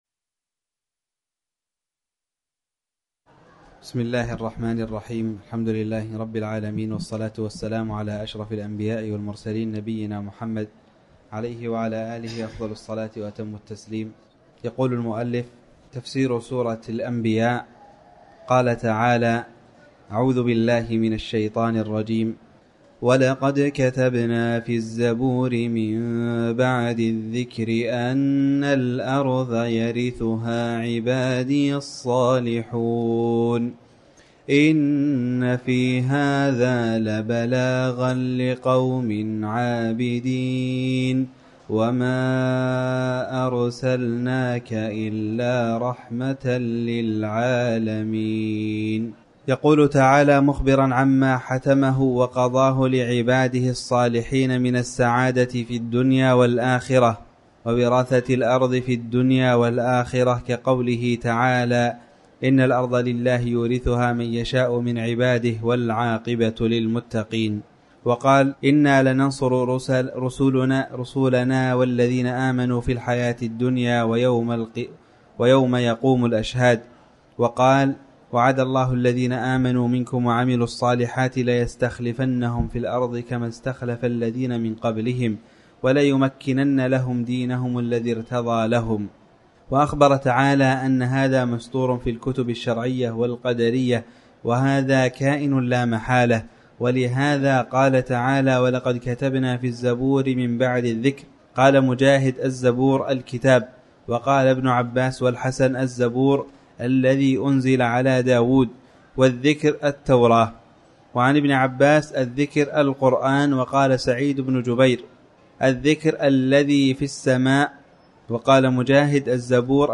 تاريخ النشر ١٥ شوال ١٤٤٠ هـ المكان: المسجد الحرام الشيخ